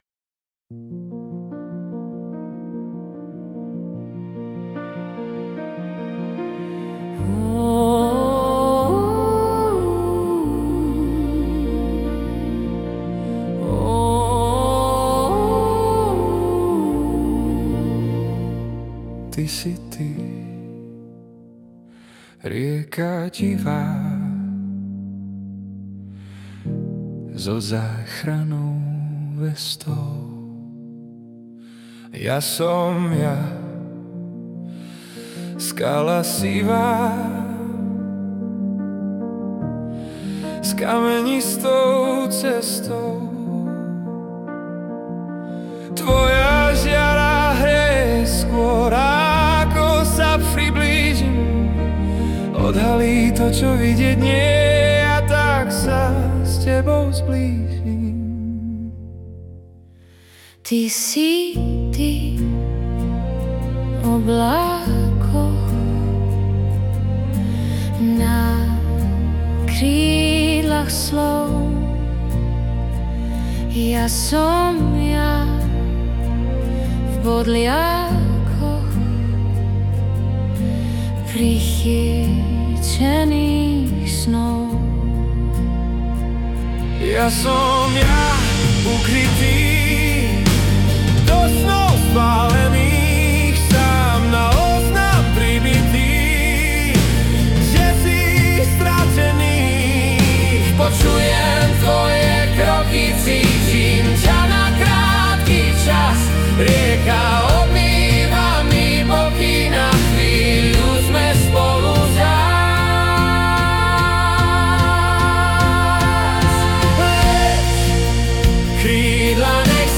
Hudba a spav AI
Balady, romance » Láska